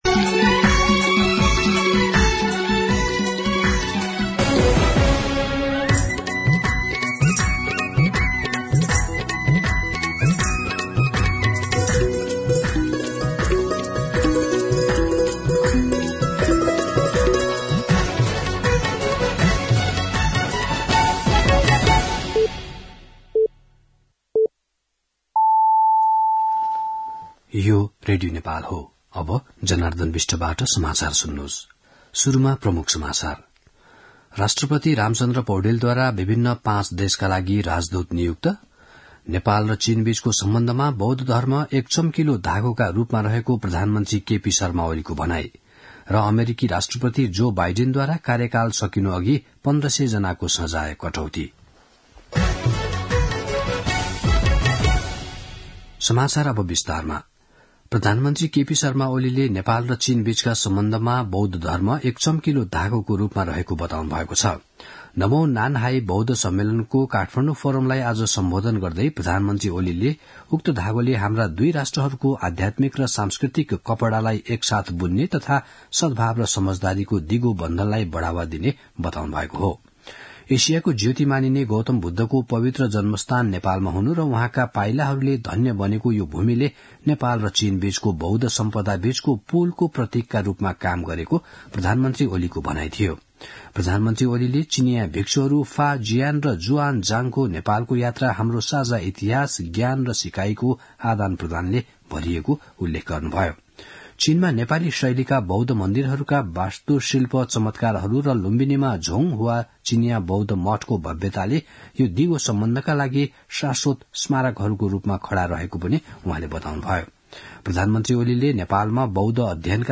दिउँसो ३ बजेको नेपाली समाचार : २९ मंसिर , २०८१
3-pm-nepali-news.mp3